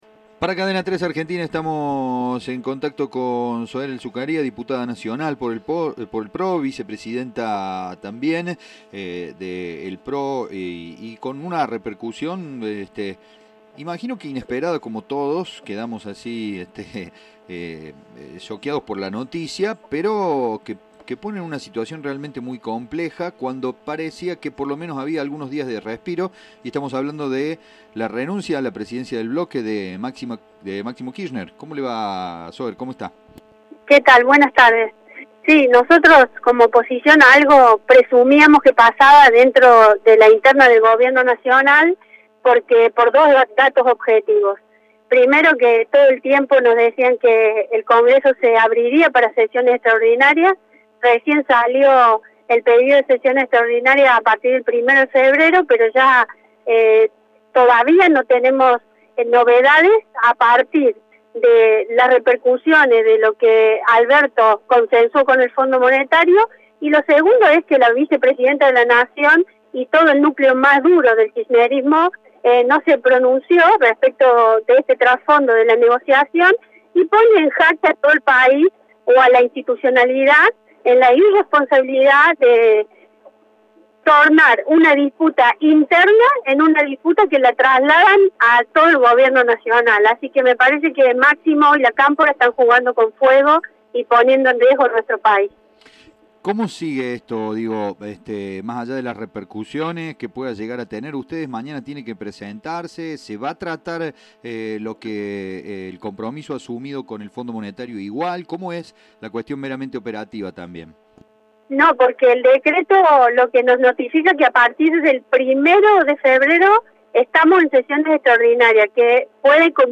La diputada nacional por el Pro aseguró a Cadena 3 que el kirchnerismo puso "en jaque a la institucionalidad del país".